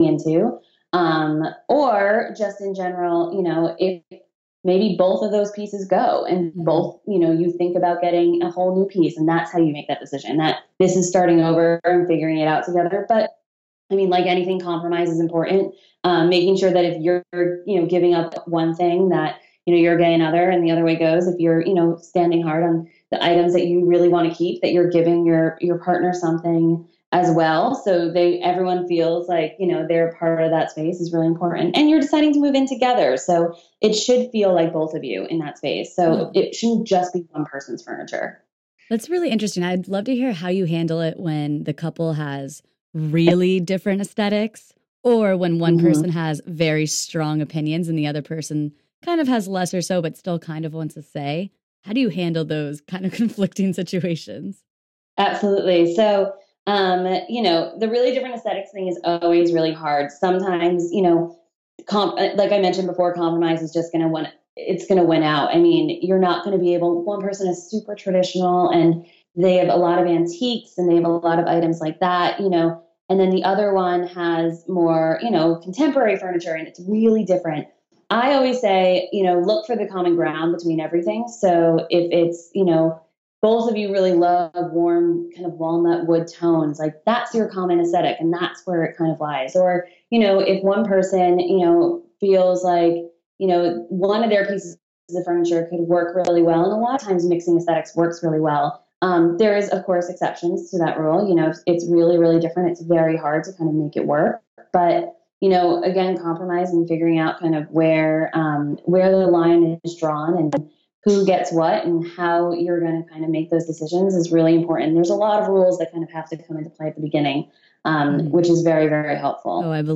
chats with interior designer